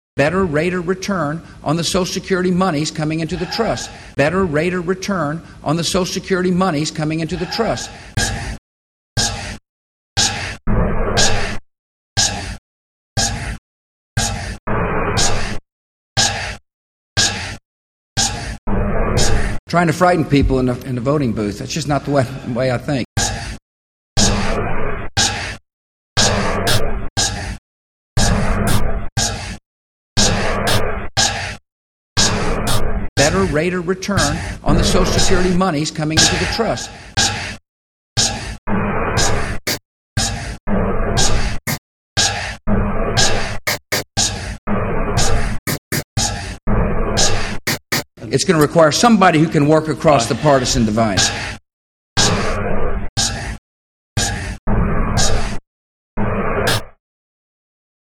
Made this political musique concrete a few years ago for a group project I was invited to participate in and then rejected from as soon as this hit the curator's inbox!
The debate was still up on one of the network websites so I recorded some of the sighs and made a music piece with them.
It unfortunately also includes some soundbites of Bush lying about, among other things, whether private banks would give citizens a "better rate of return" on their Social Security dollars.